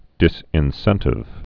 (dĭsĭn-sĕntĭv)